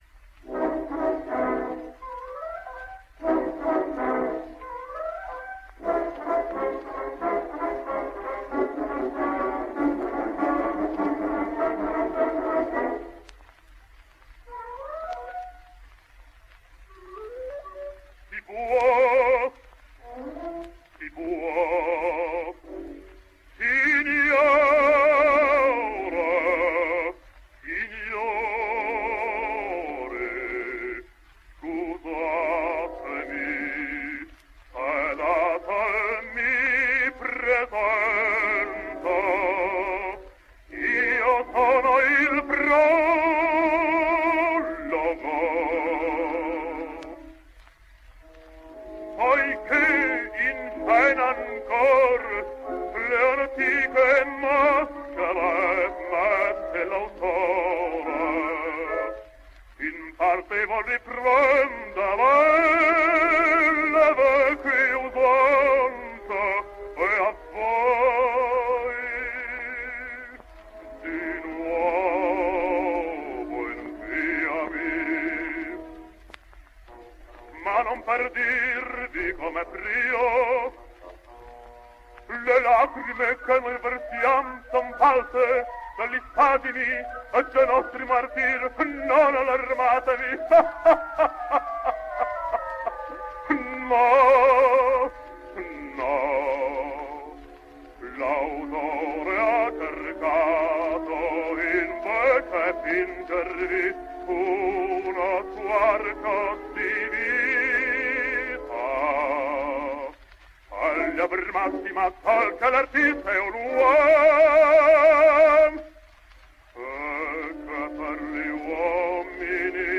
So, our first records must show him as a baritone, for he is unique, in that he is the only singer in this survey, to have been one of the world’s finest baritones and then become one of the world’s finest tenors.